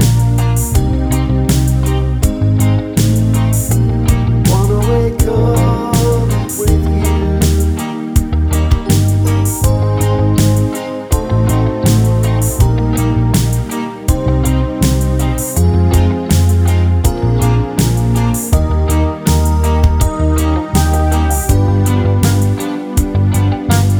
no Backing Vocals Reggae 3:03 Buy £1.50